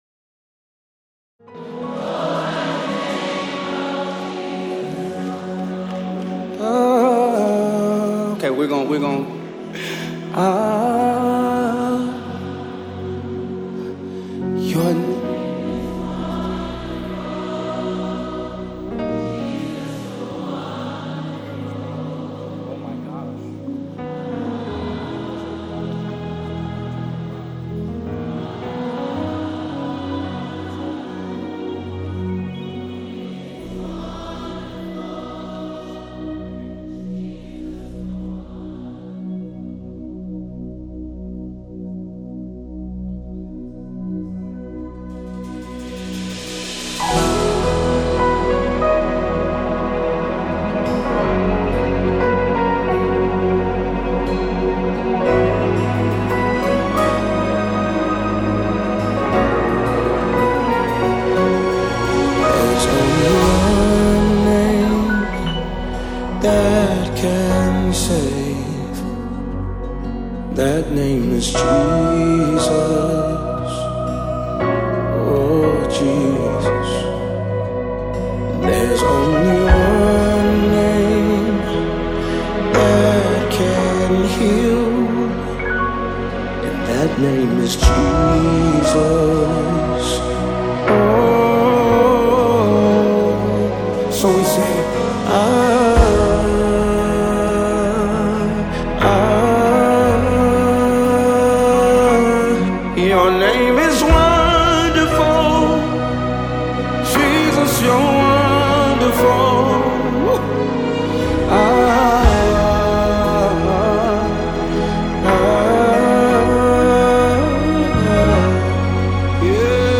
Gospel Music
powerful worship song